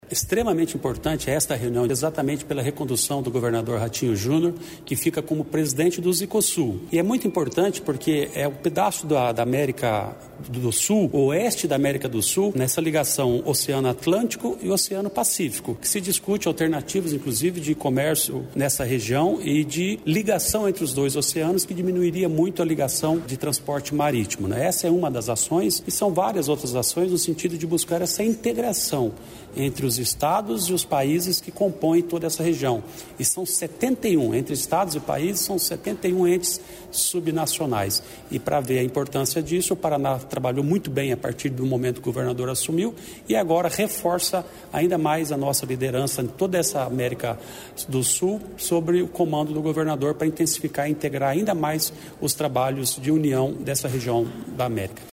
Sonora do secretário de Estado do Planejamento, Ulisses Maia, sobre a recondução do Estado na presidência da Zicosul